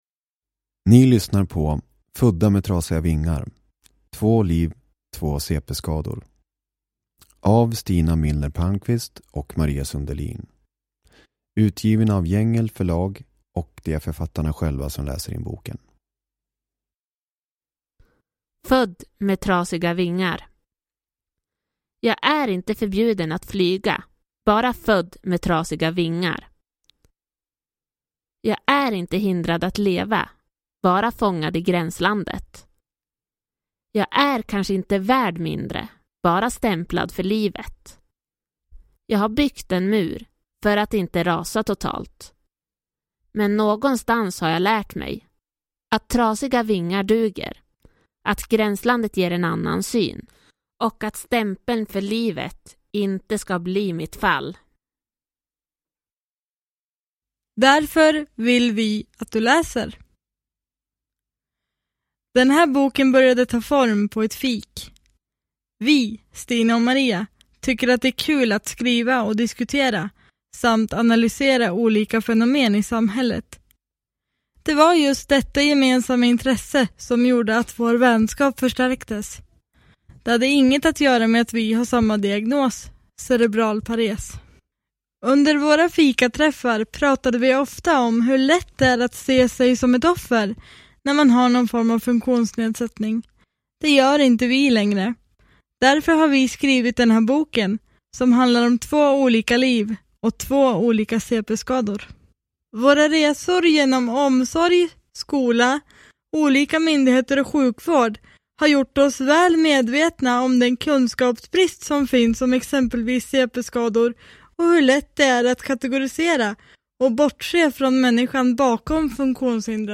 Födda med trasiga vingar – Ljudbok – Laddas ner